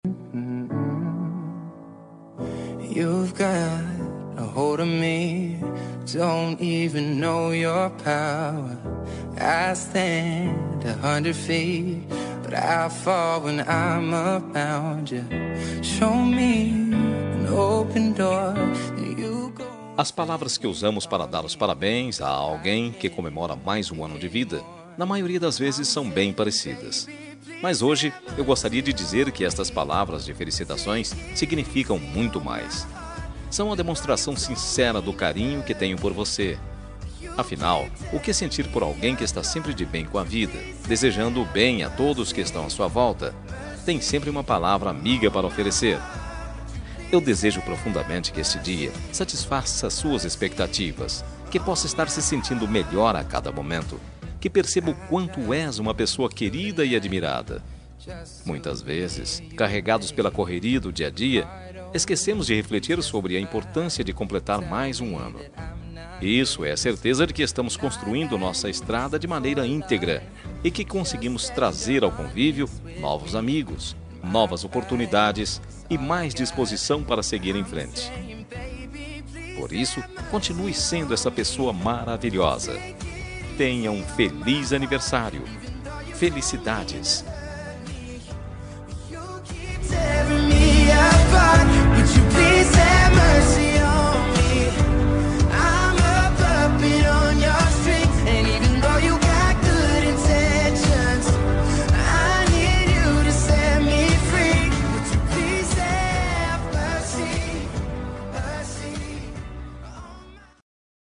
Aniversário de Ficante – Voz Masculina – Cód: 8882
aniv-ficante-masc-8882.m4a